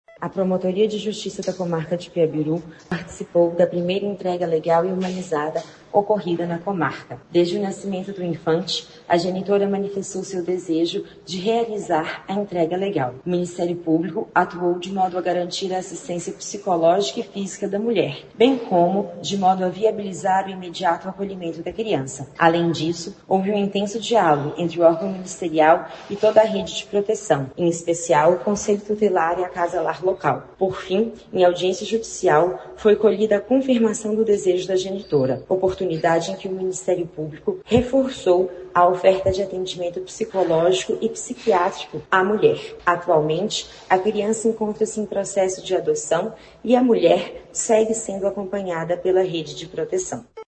O processo em Peabiru foi concluído em fevereiro e acompanhado pelo Ministério Público. Ouça o que diz a promotora de Justiça Renata Melo Ventura.